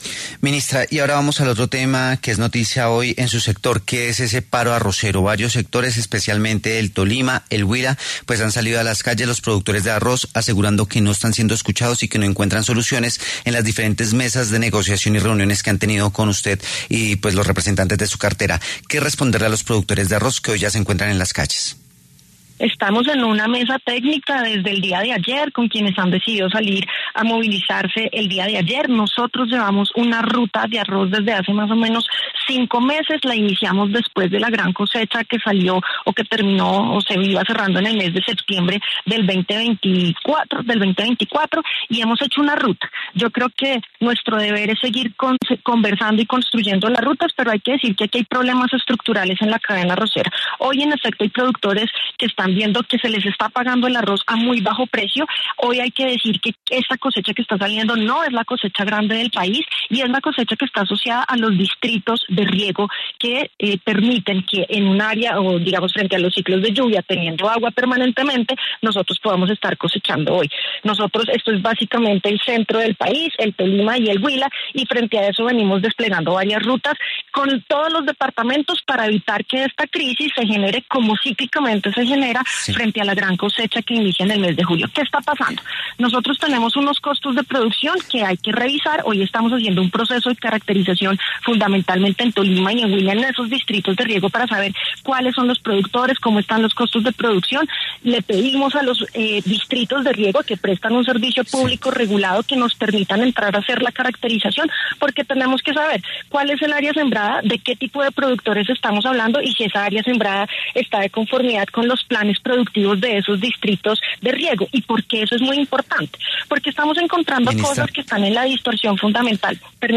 A propósito de esto, La W conversó con la ministra de Agricultura, Martha Carvajalino, quien aseguró que actualmente están llevando a cabo una mesa técnica para llegar a un acuerdo.